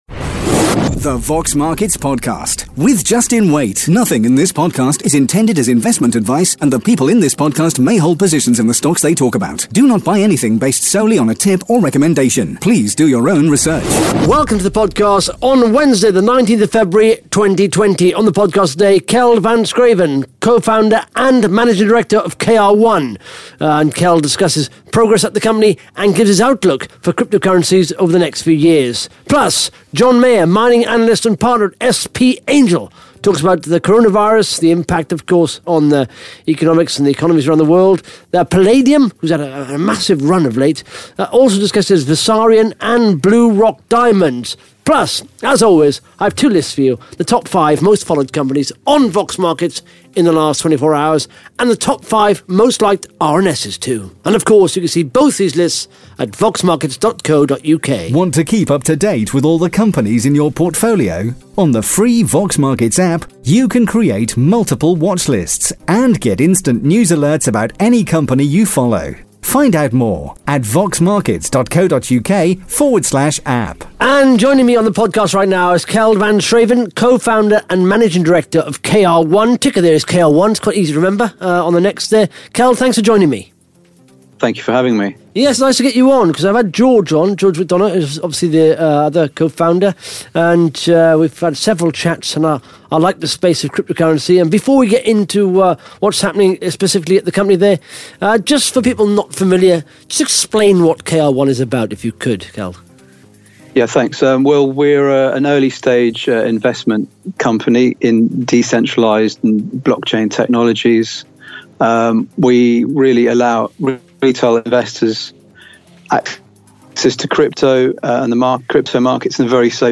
(Interview starts at 23 minutes 35 seconds)